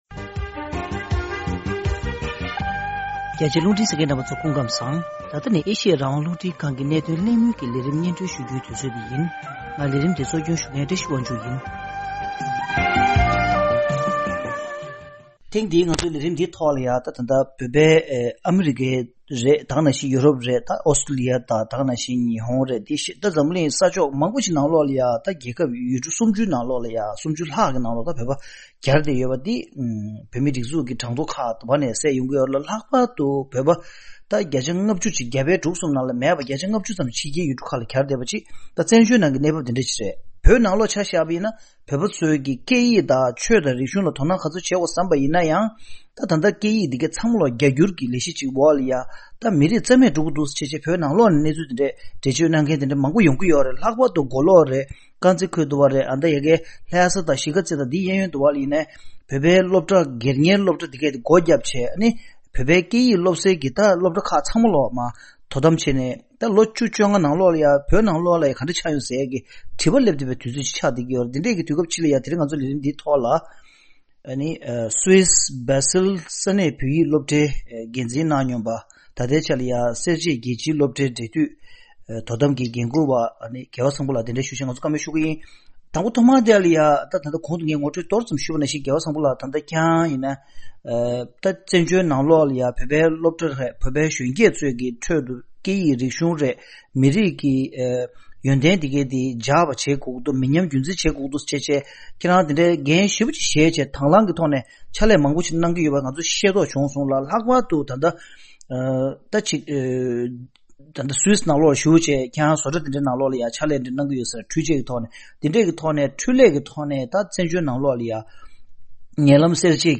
གླེང་མོལ་ཞུས་པ།བོད་མི་བཙན་བྱོལ་བ་མང་དག་ཅིག་ཕྱི་རྒྱལ་ཡུལ་གྲུ་ཁག་ཏུ་གྱར་བཞིན་པས་བོད་ཀྱི་ཆོས་དང་རིག་གཞུང་སྐད་ཡིག་དང་བཅས་པ་མི་ཉམས་རྒྱུན་འཛིན་བྱེད་ཐོག གཞོན་སྐྱེས་མི་རབས་ལ་རྩིས་སྤྲོད་བྱེད་རྒྱུ་ནི་སྐབས་བབས་ཀྱི་གདོང་ལེན་བྱེད་པའི་གནད་དོན་གལ་ཆེན་ཞིག་ཏུ་གྱུར་ཡོད་པ་རེད།